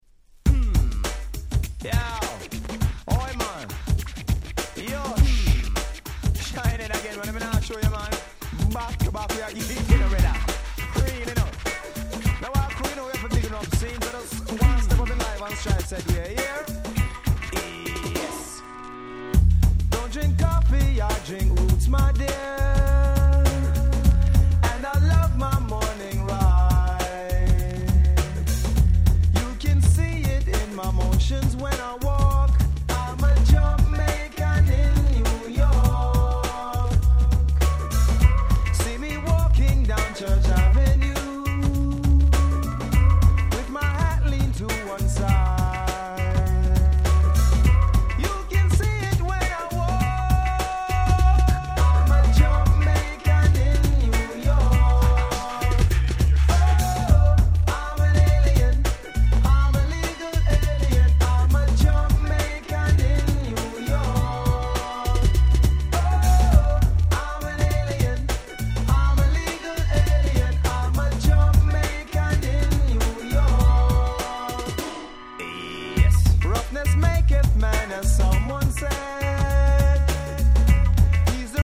92' Super Hit Ragga !!